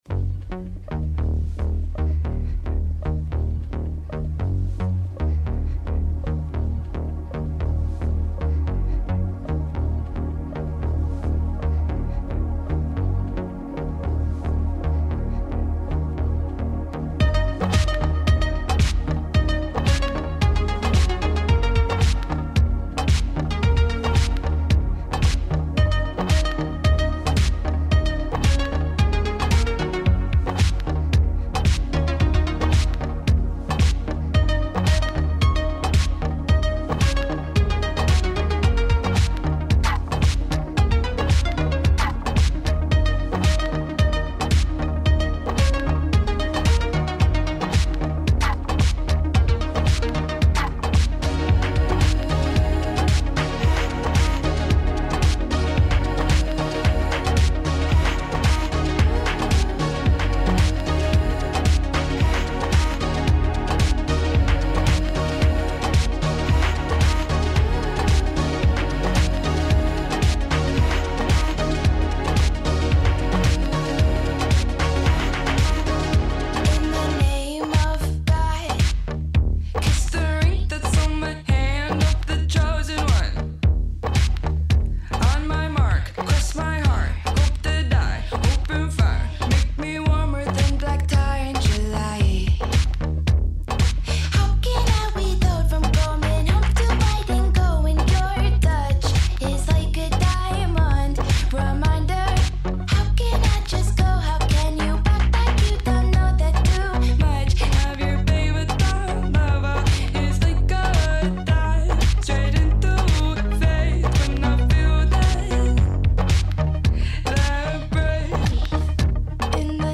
your boy likes indie pop